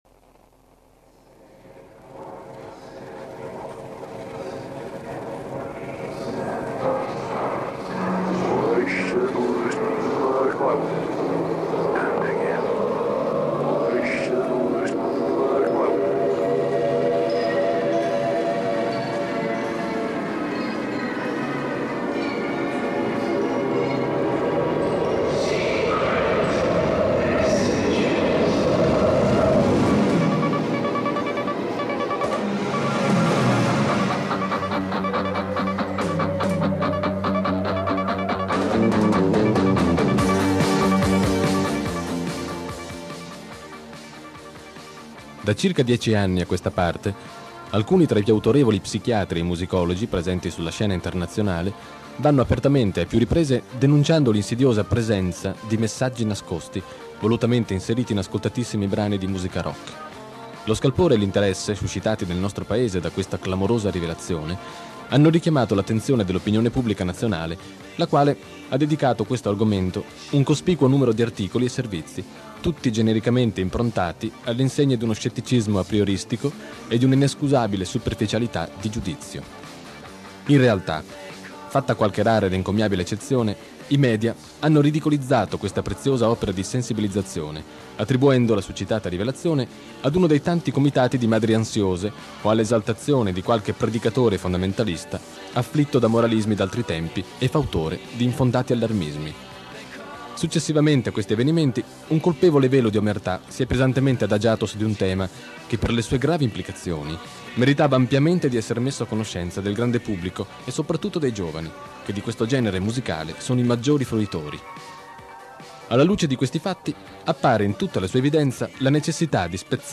audio conferenza rock satanico